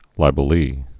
(lībə-lē)